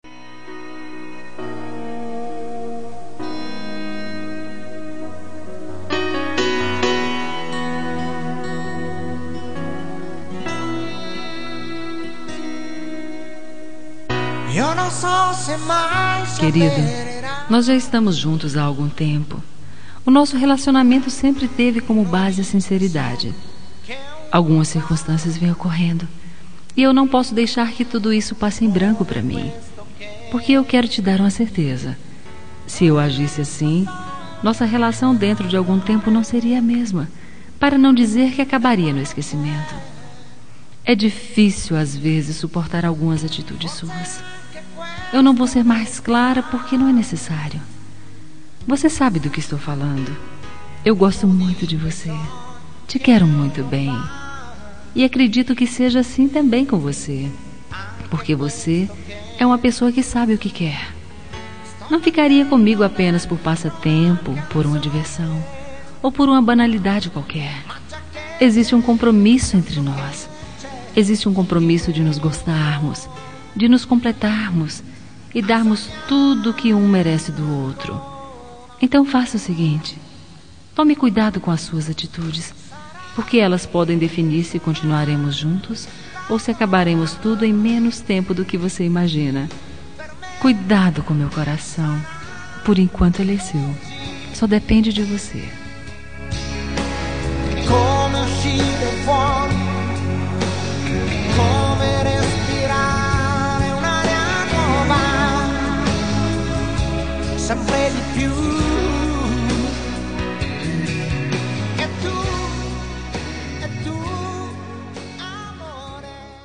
Toque para Não Terminar – Voz Feminina – Cód: 470 – Desencanto
470-desencnto-fem.m4a